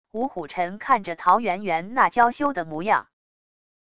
吴虎臣看着陶园园那娇羞的模样wav音频生成系统WAV Audio Player